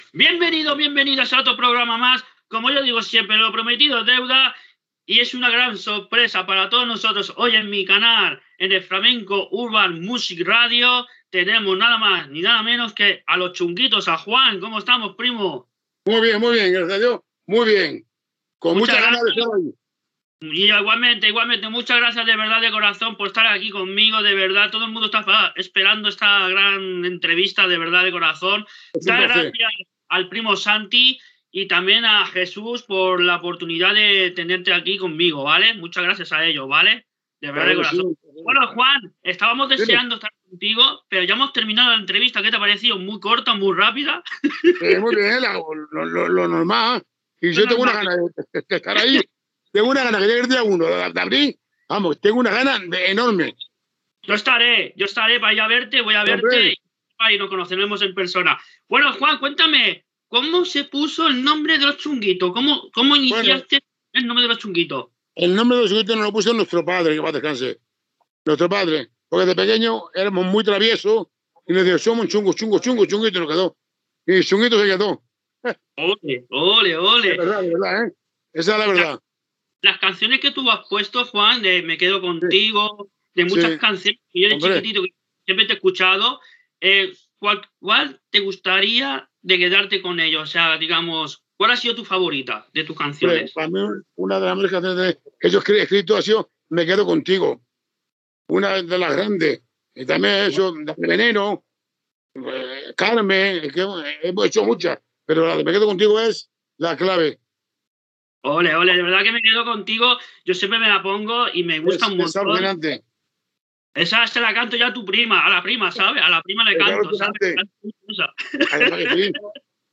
Entrevista a Juan Salazar del grup Los Chunguitos. S'hi parla del nom del grup, del concert que farà a Barcelona, en solitari, i d'un nou tema que prepara